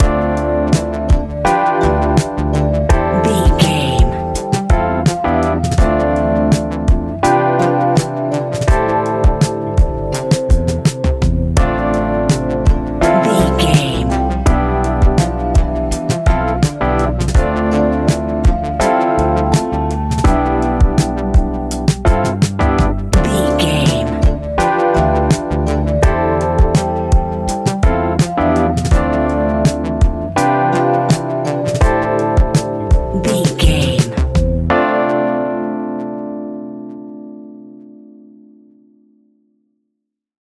Ionian/Major
D
laid back
Lounge
sparse
new age
chilled electronica
ambient
atmospheric